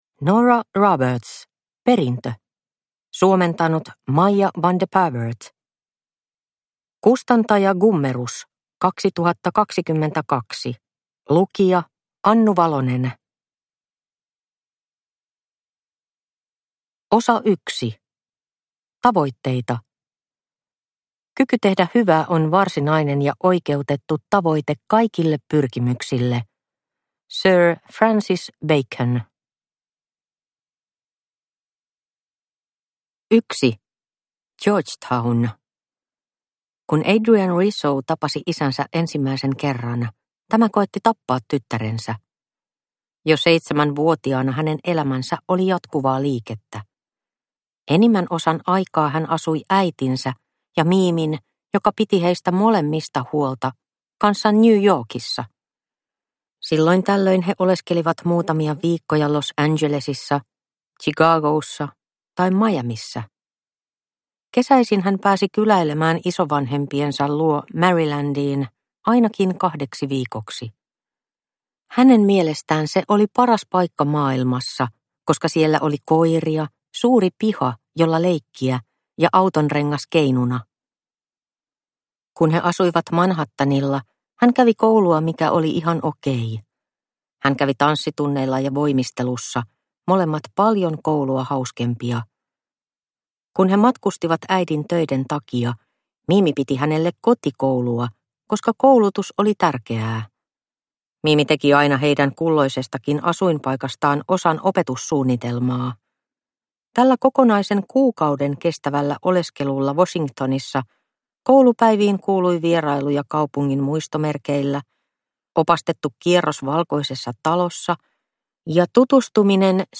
Perintö – Ljudbok – Laddas ner